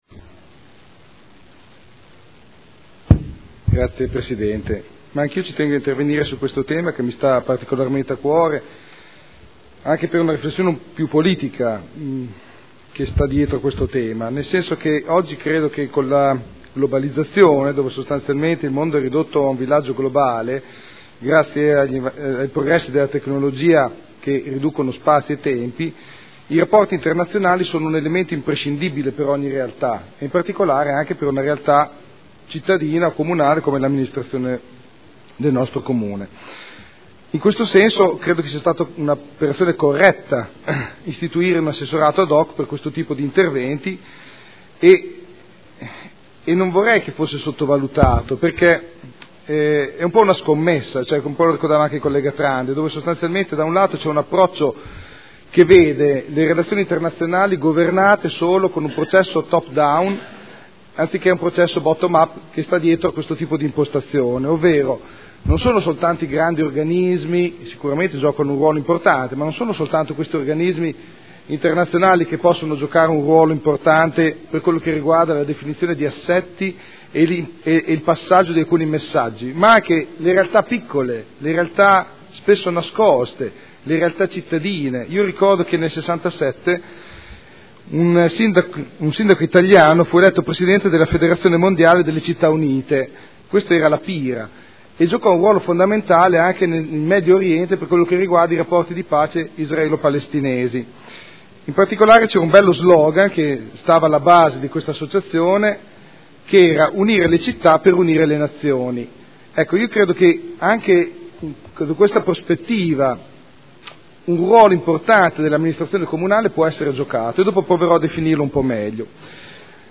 Enrico Artioli — Sito Audio Consiglio Comunale
Proposta di deliberazione: Regolamento per la costituzione, la gestione e lo sviluppo di relazioni internazionali con città, comunità e territori. Dibattito